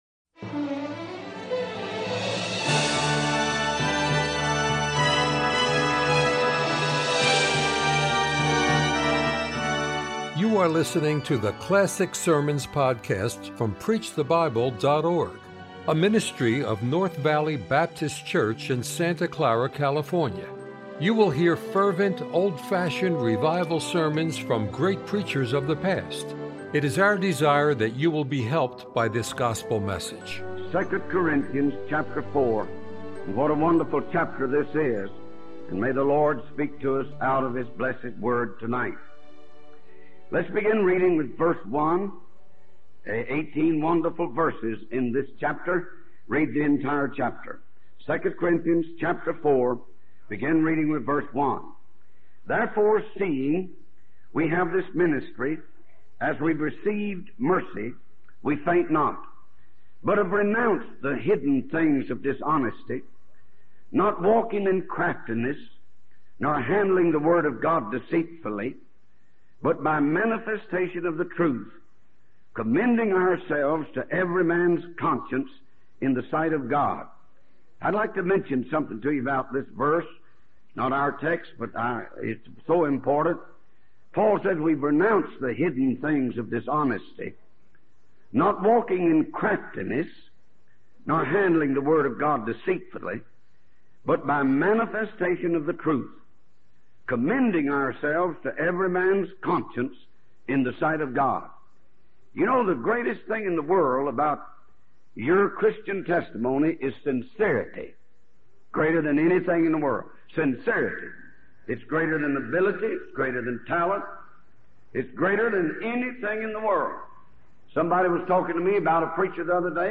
We hope you enjoy this classic sermon from a great preacher of the past.